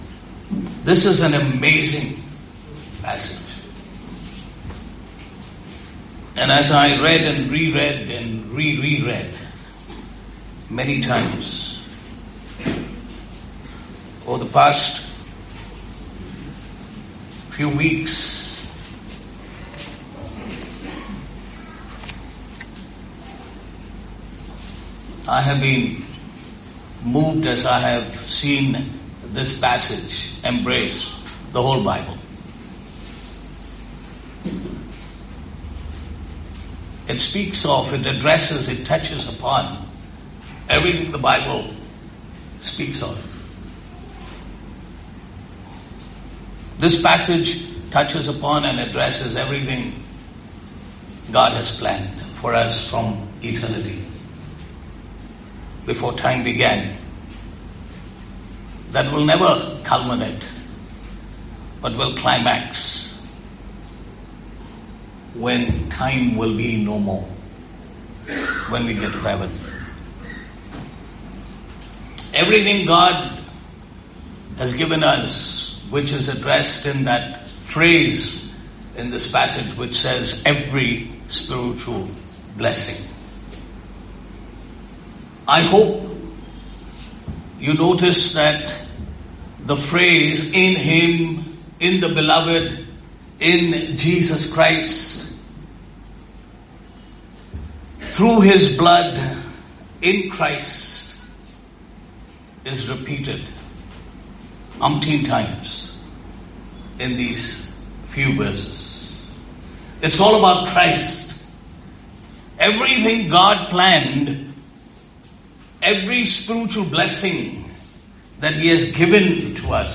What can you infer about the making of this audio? Passage: Ephesians 1:3-14 Service Type: Good Friday